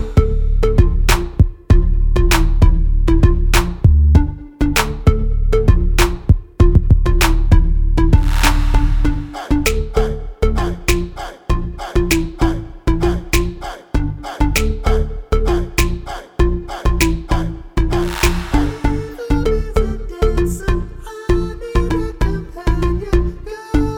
no Backing Vocals R'n'B / Hip Hop 4:27 Buy £1.50